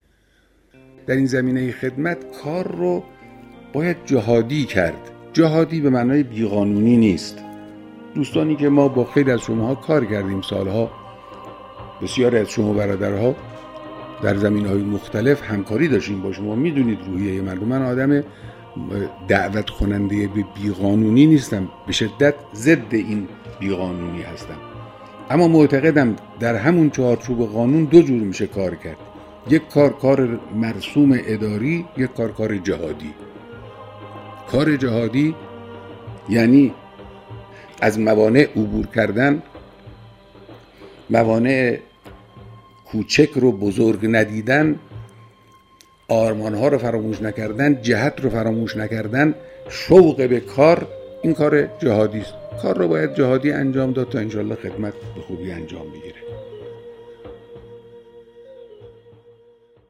صوت بیانات رهبر انقلاب اسلامی, صوت بیانات مقام معظم رهبری